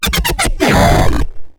herodeath.wav